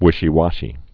(wĭshē-wŏshē, -wôshē)